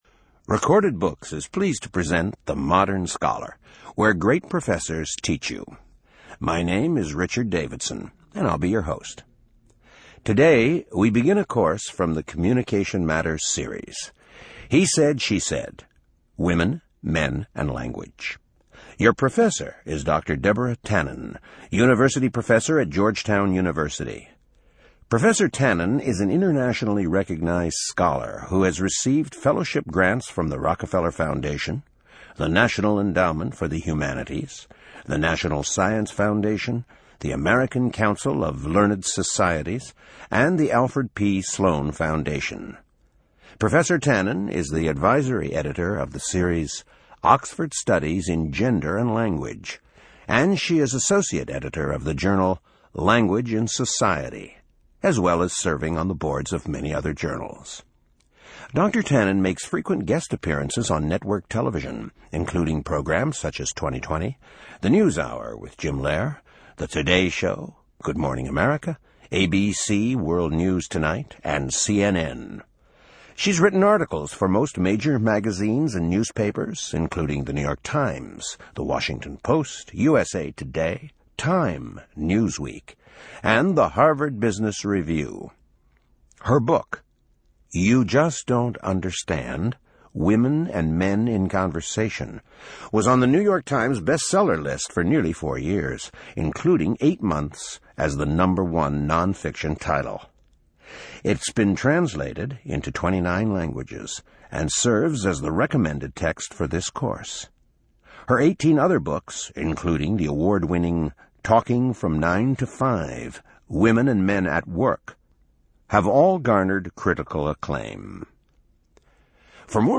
In this lecture linguistics professor and bestselling author Deborah Tannen describes many of the basic differences between in communication between men and women.